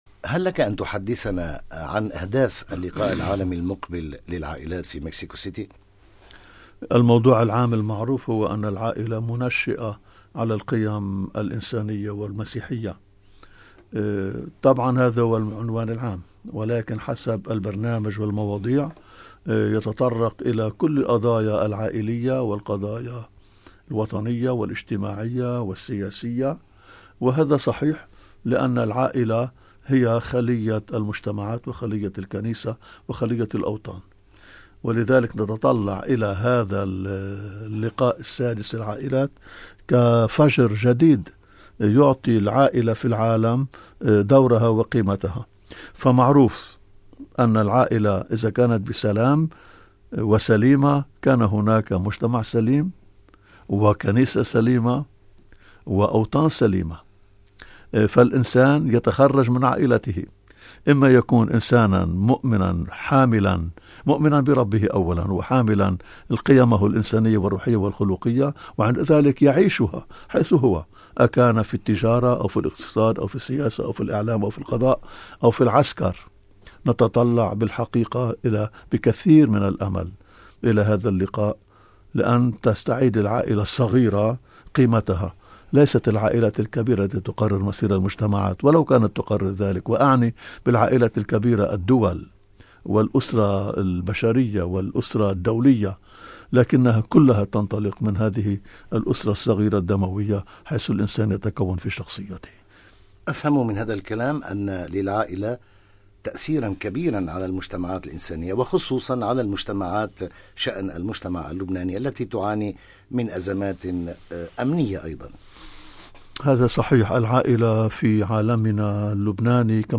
استضاف القسم العربي في إستديو إذاعة الفاتيكان رئيس أساقفة جبيل الماروني المطران بشارة الراعي وهو المنتخب حديثا رئيسا للجنة الأسقفية لوسائل الإعلام في لبنان، الذي حدثنا عن مشاركته المرتقبة في المؤتمر العالمي السادس للعائلات في مكسيكو سيتي بالمكسيك وعن دور العائلة وقيمها في المجتمع الشرق الأوسطي.